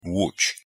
Łódź (pronounced as
Woodge) is a post-industrial city that offers many tourist attractions based on 19th century cotton industry heritage.
pronunciation_pl_lodz.mp3